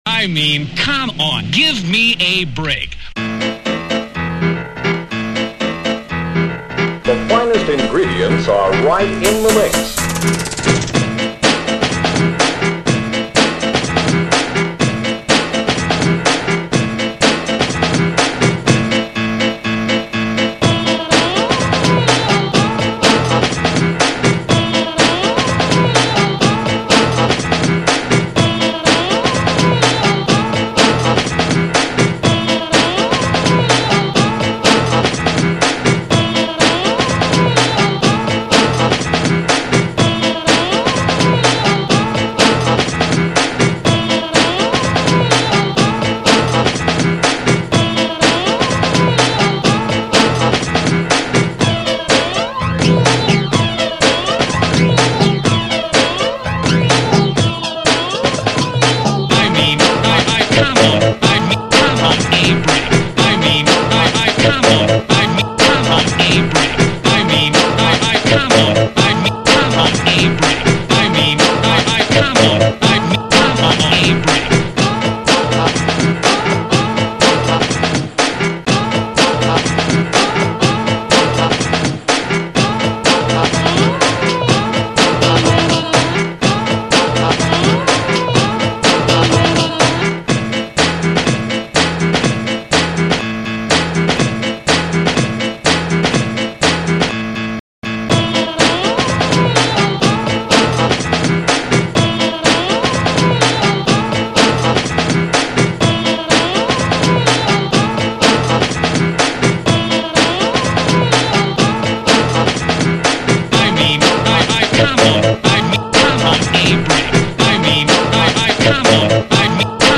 Веселенькая темка break beat